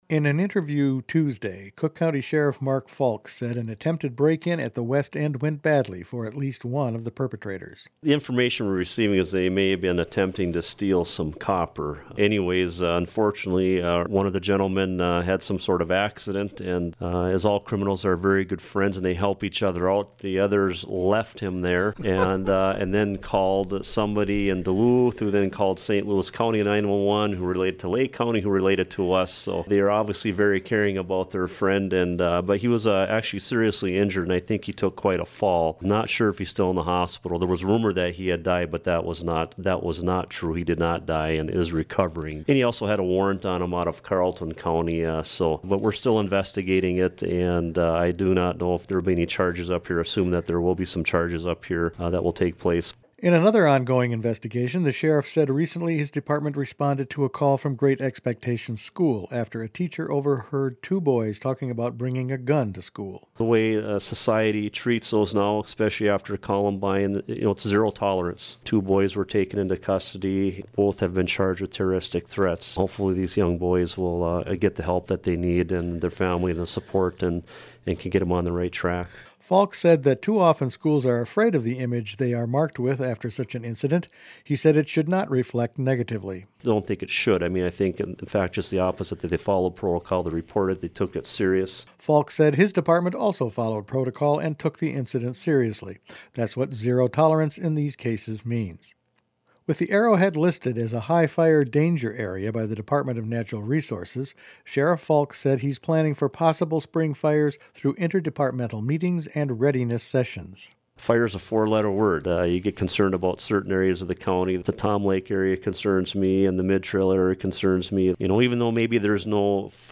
In an interview Tuesday, Cook County Sheriff Mark Falk said an attempted break-in at the West End went badly for at least one of the perpetrators.